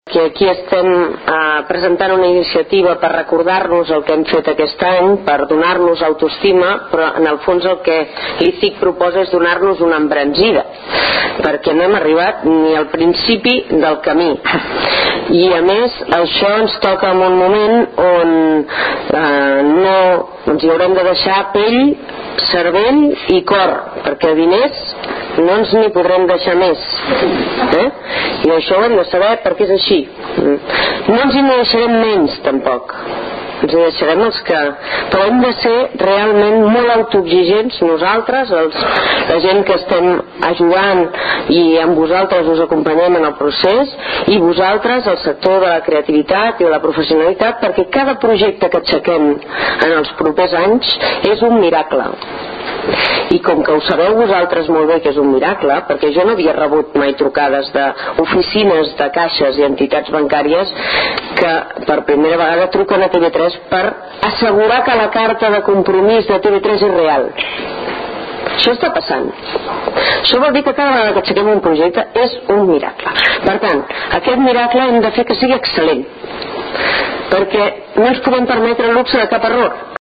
Intervencions durant la roda de premsa
Tall de veu de Mònica Terribas